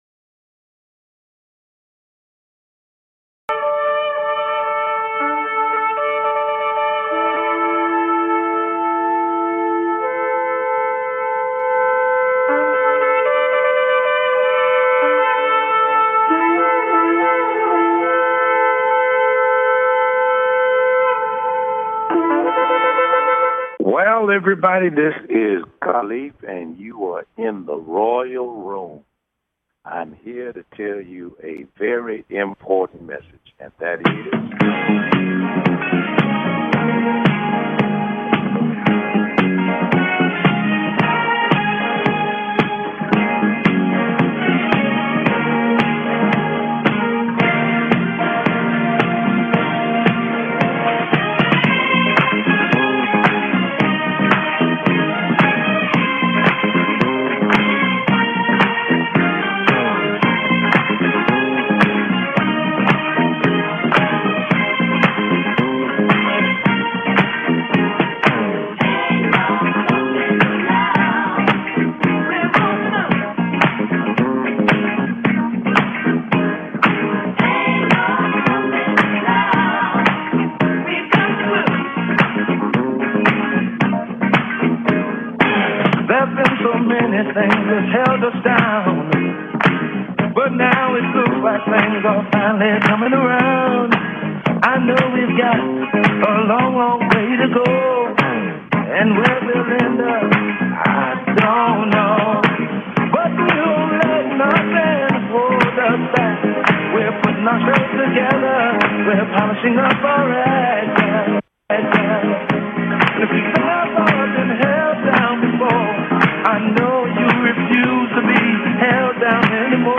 Talk Show Episode